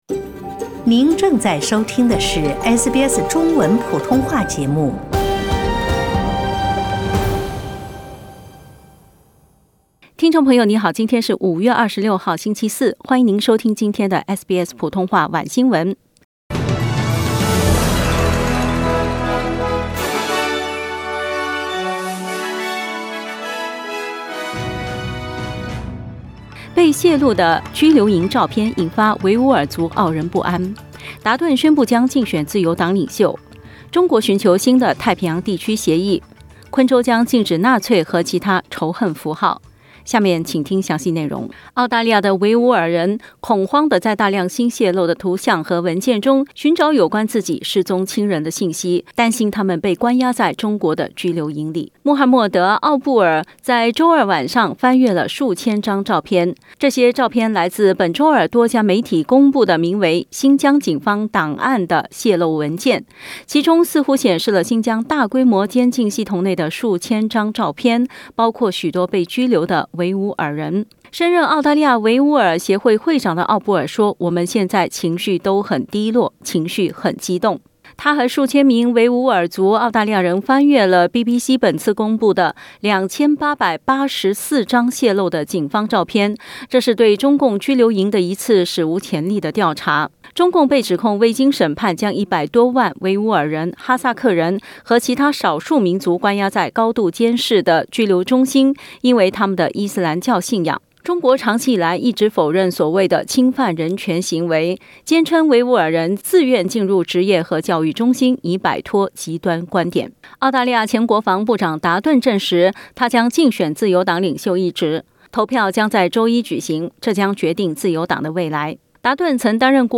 SBS Mandarin evening news Source: Getty Images